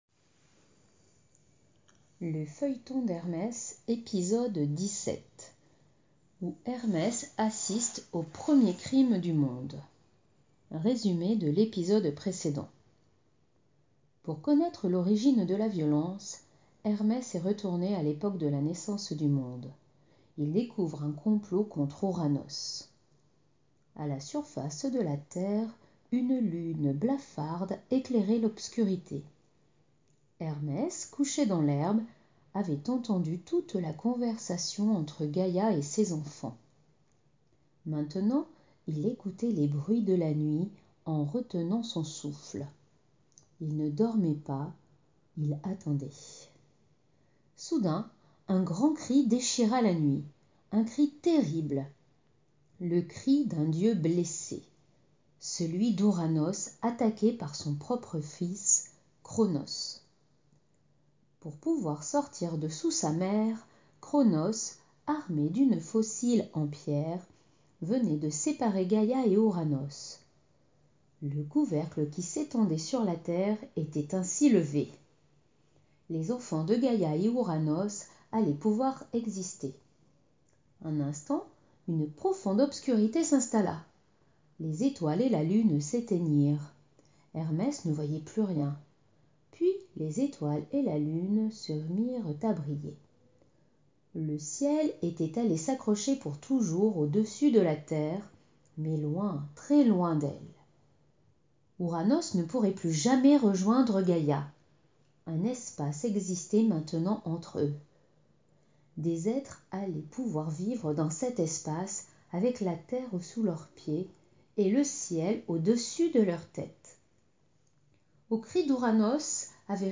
Lecture de l'épisode 17 du Feuilleton d'Hermès.